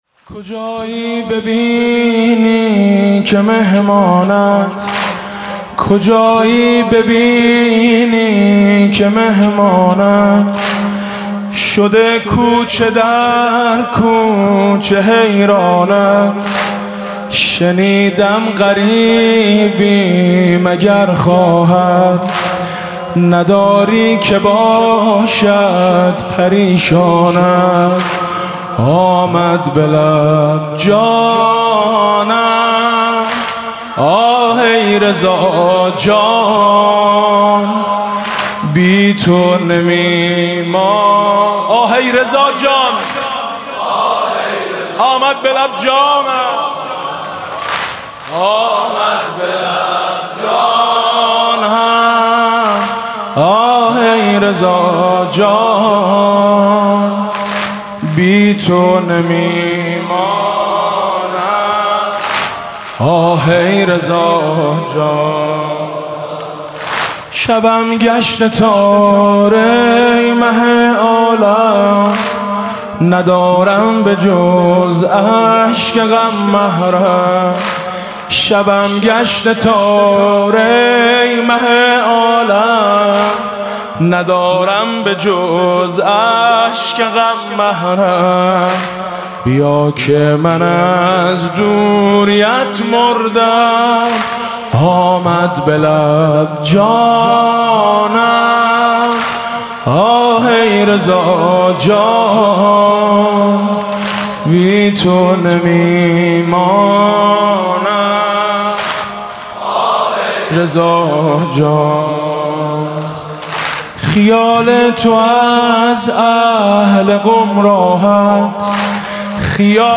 ویژه وفات حضرت معصومه (واحد)